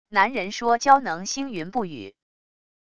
男人说蛟能兴云布雨wav音频